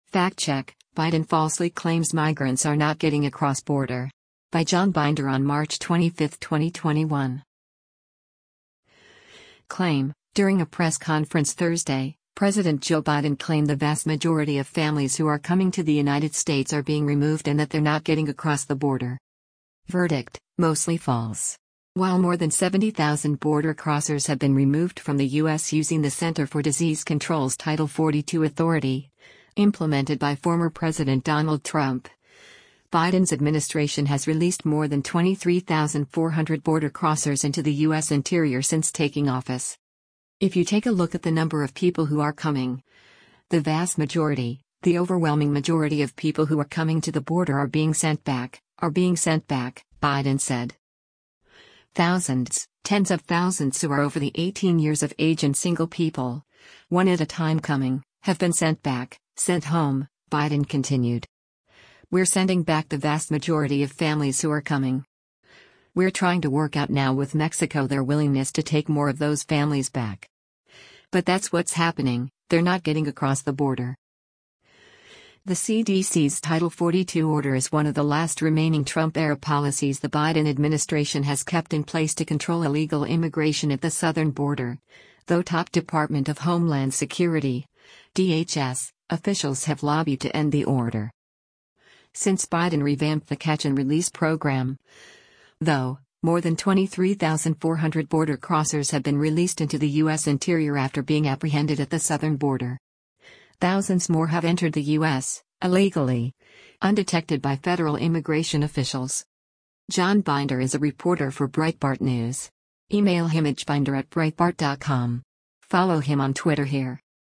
CLAIM: During a press conference Thursday, President Joe Biden claimed “the vast majority of families who are coming” to the United States are being removed and that “they’re not getting across the border.”